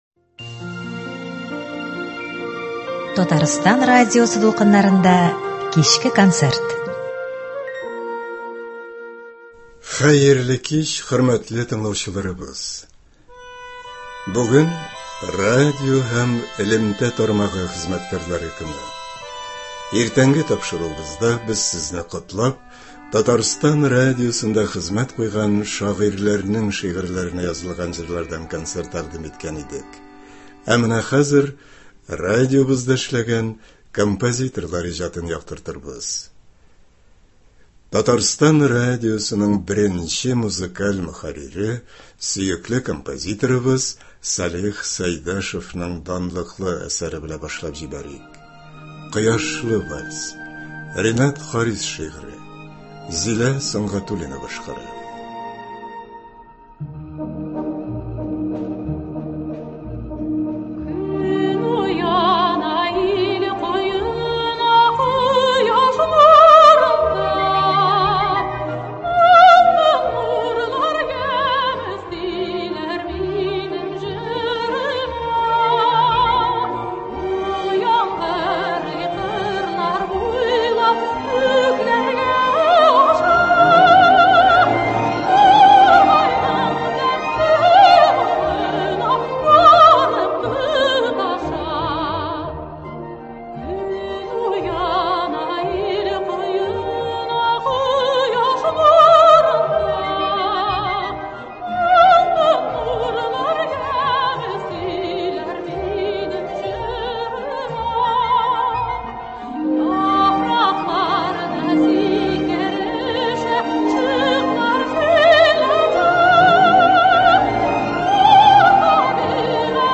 Концерт (07.05.24)